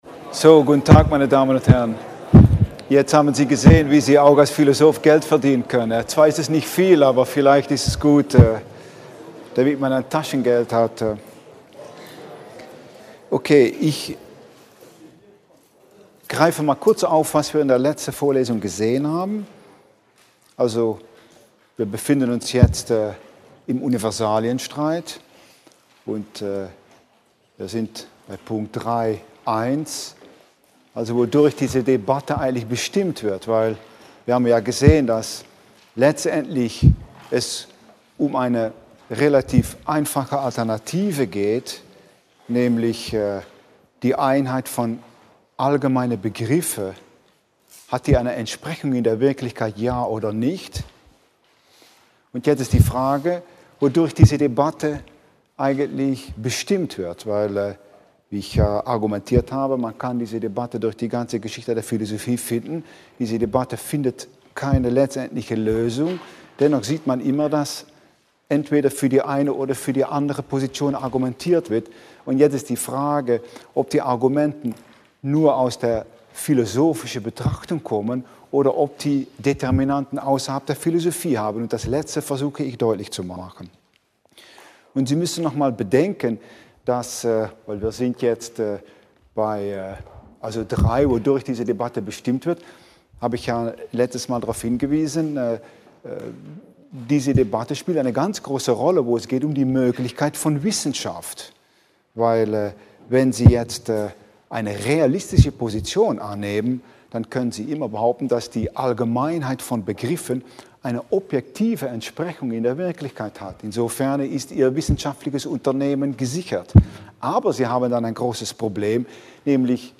5. Vorlesung: 22.11.2010